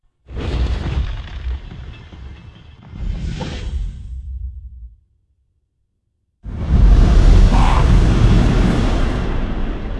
Дыхание монстра:
monsters1.wav